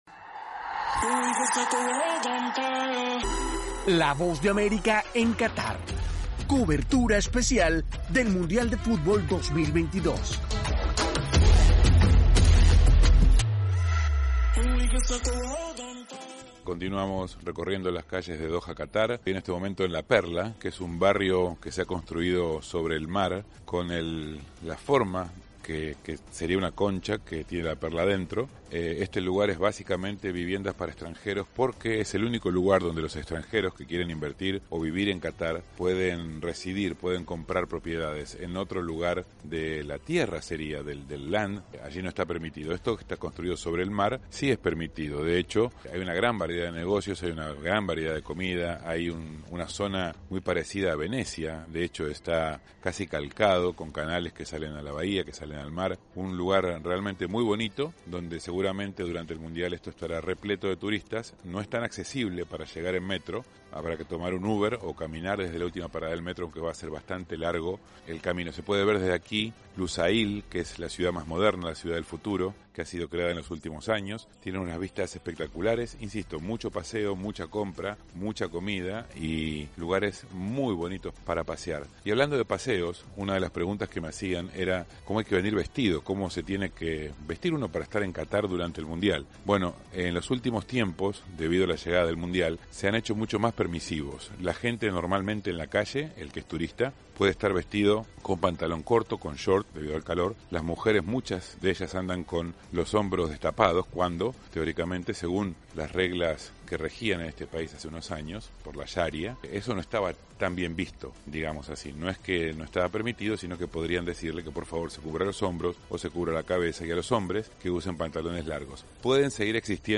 enviado especial desde Doha